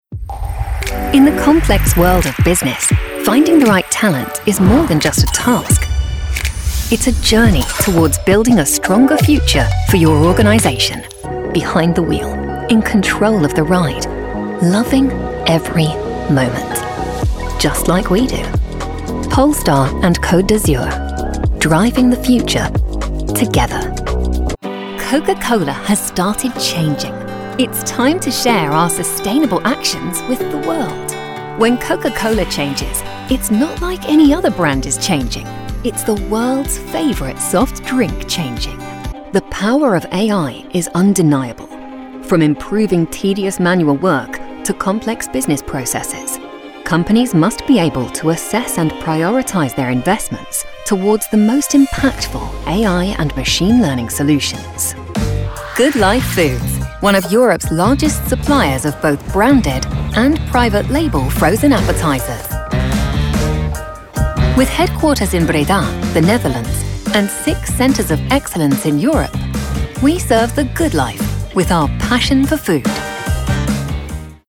Corporate Reel
accented, accented English, anti-announcer, british, classy, confident, cool, corporate, professional, smooth, upbeat, worldly